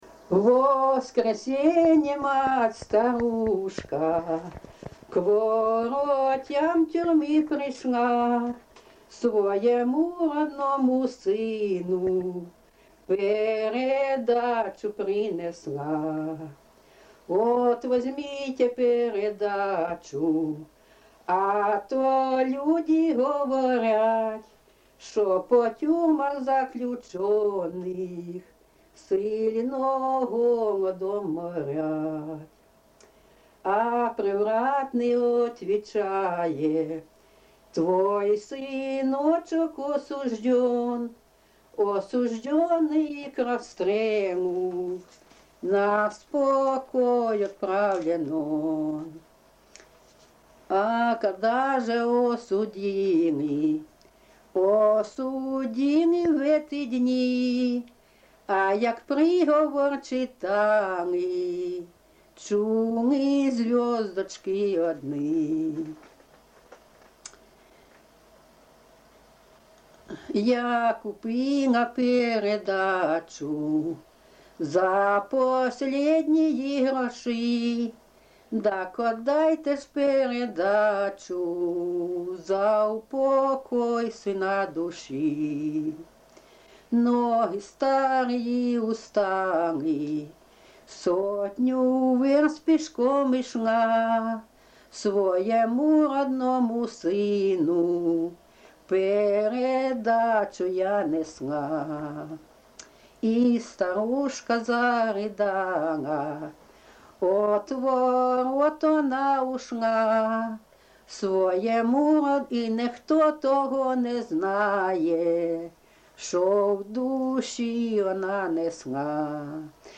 ЖанрТюремні, Сучасні пісні та новотвори